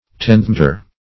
Tenthmetre \Tenth"me`tre\, n.
tenthmetre.mp3